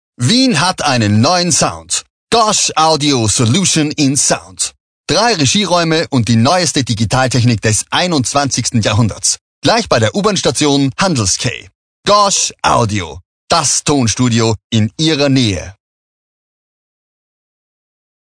SPRECHER DEMO Hochdeutsch - WERBETEXT GOSH AUDIO 3 - mehr Power.mp3